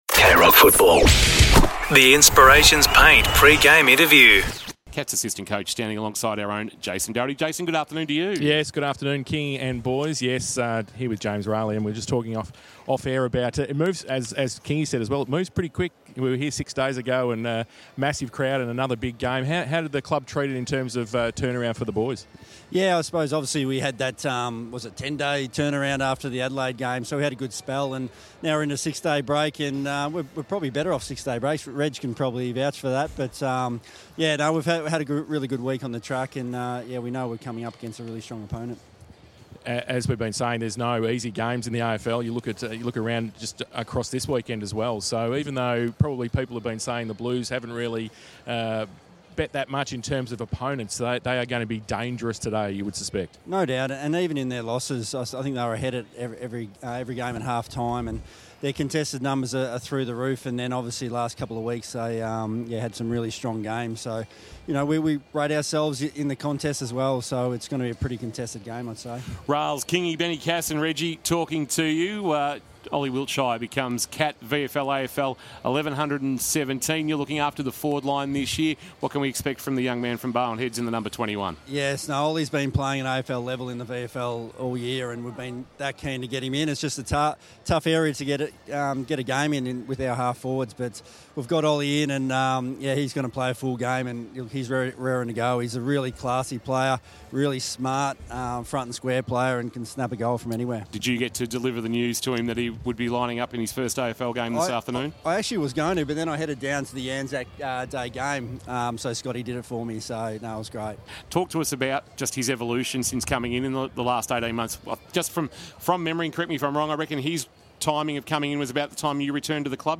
2025 - AFL - Round 7 - Carlton vs. Geelong: Pre-match interview